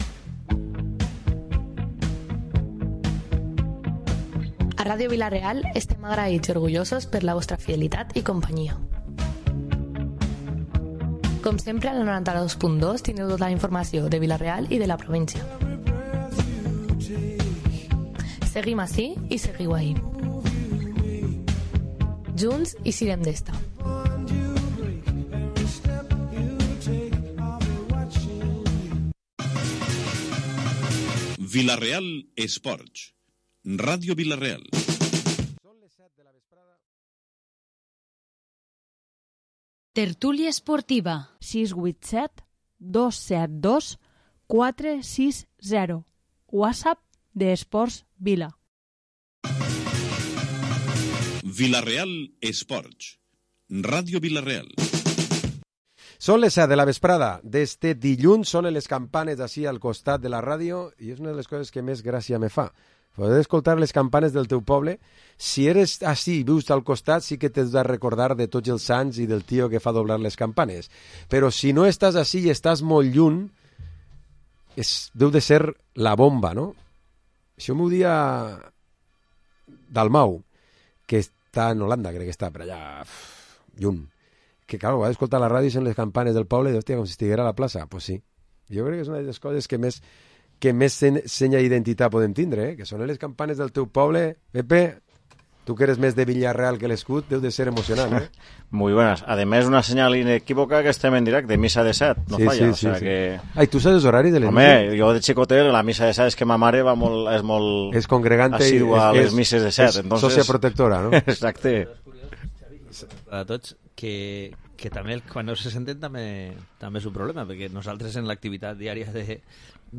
Programa esports tertúlia dilluns 2 d’octubre